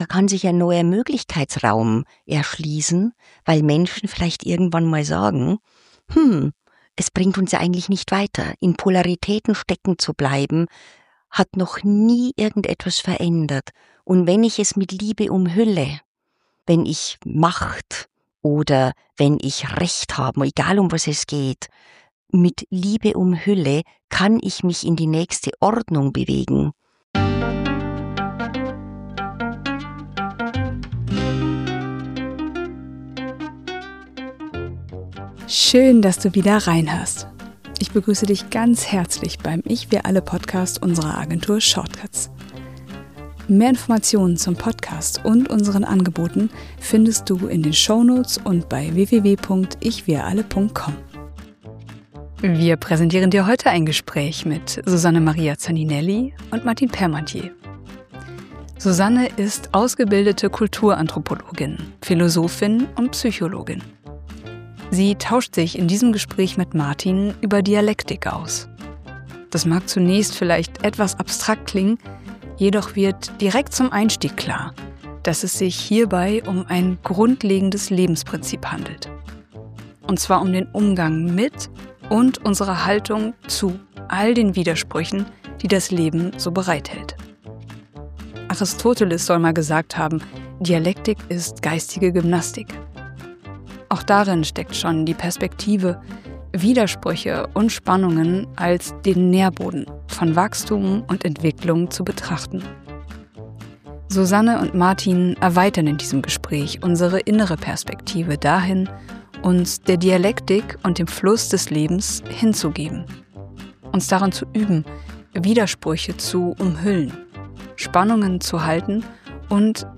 Ein Gespräch darüber, dass Widersprüche keine Fehler im System sind.